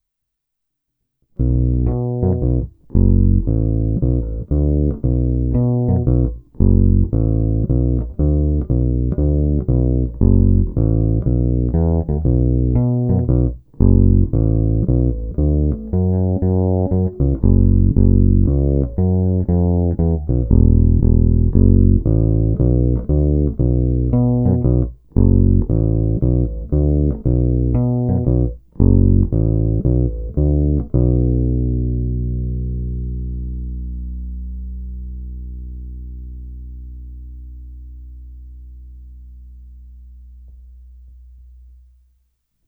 Snímač je humbucker od neznámé firmy, ovšem má dobrý zvuk.
No a tahle Jolana Iris Bass mňouká a to krásně.
Hra prsty u kobylky dává zvuk ostrý, chraplavý, u krku měkce kontrabasový a mňoukavý, lehce perkusní. Ve zvukových ukázkách jsou na baskytaře natažené hlazené struny Thomastik-Infeld Jazz Flat Wound JF344 ve výborném stavu.
Zvuková ukázka přímo do zvukovky